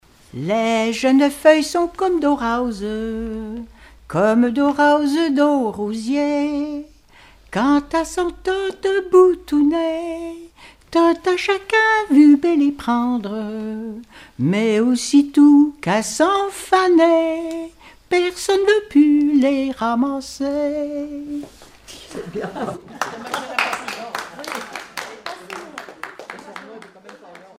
Bournezeau ( Plus d'informations sur Wikipedia ) Vendée
Genre strophique
Collectif-veillée (2ème prise de son)
Pièce musicale inédite